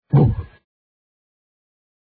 SE (番組中に流れる効果音)
ブー これもクイズでお馴染みのSE。ツッコミ時にも多用された。